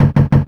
snd_knock_ch1.wav